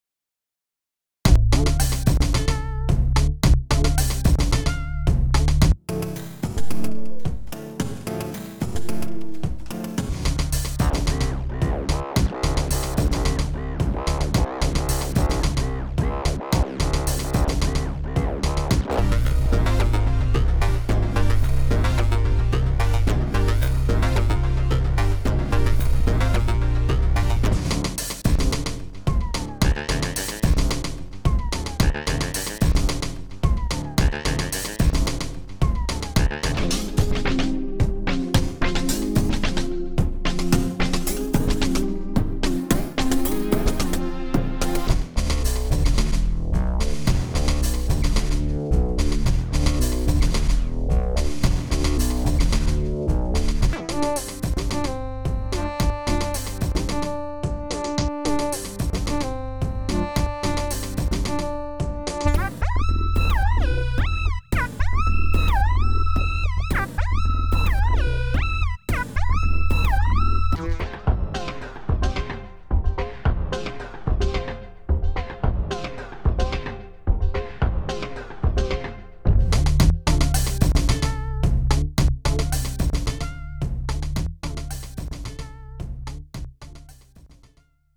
プリセットを切替えて録音した.mp3
最初は、POLYPLEXで作ったサンプルを使った演奏です。
全体的に大人しい感じがします。